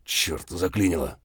gun_jam_1.ogg